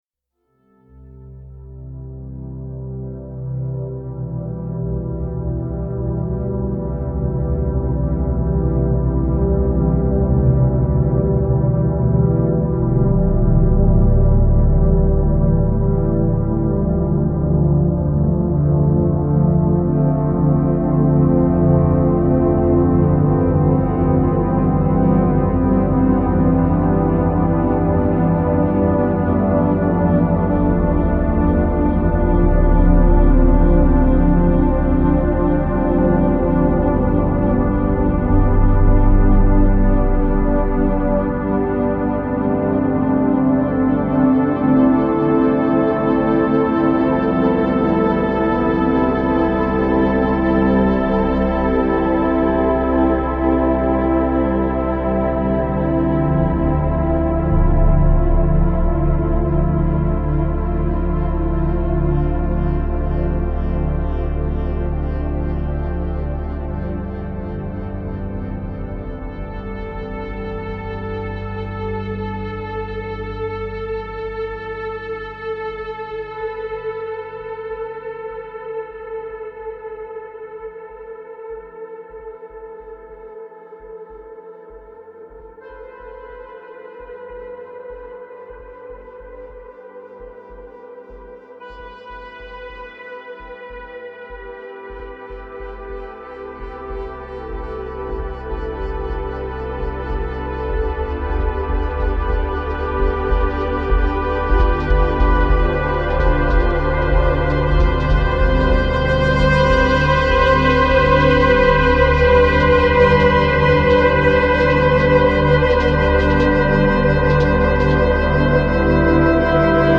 درام و آرامبخش